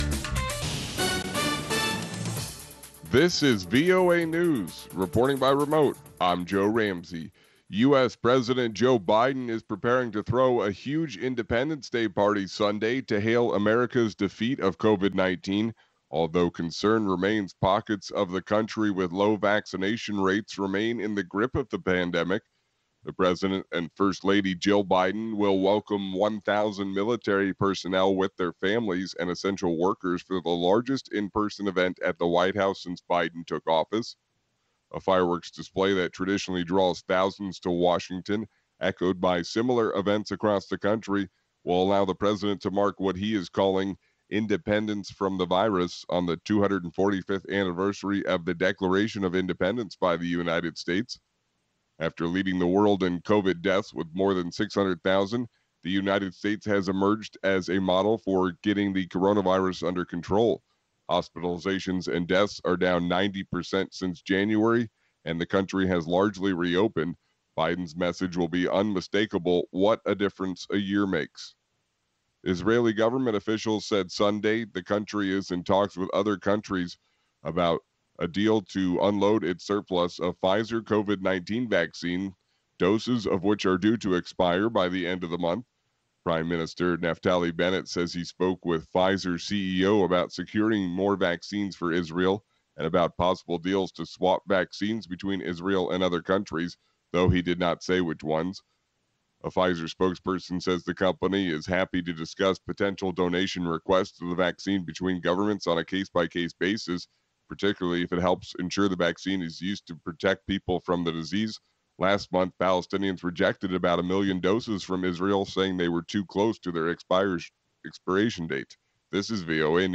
Nightline also brings you African arts and culture, country profiles, sports, music and commentary by top African experts.